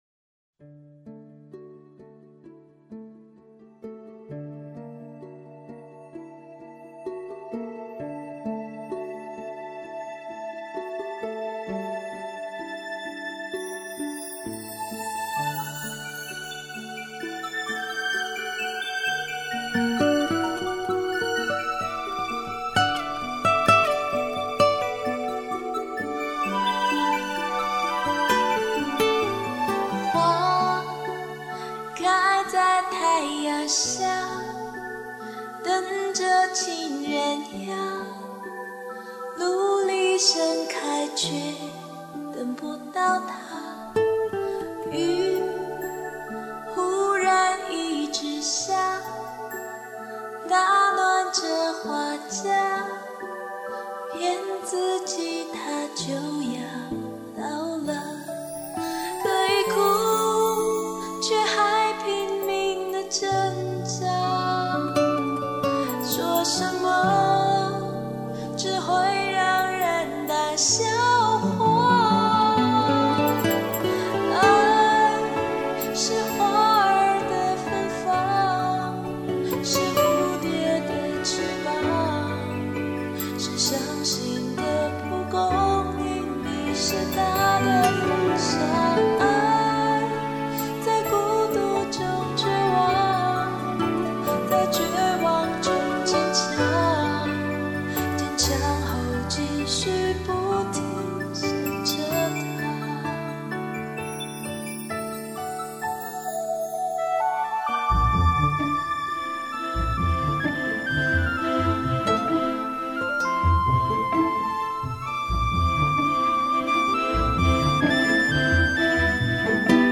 伴奏的音质太好了~